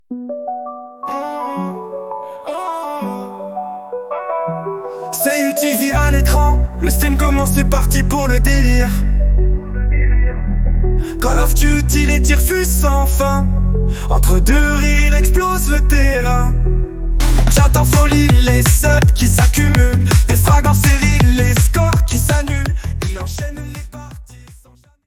Style : Latino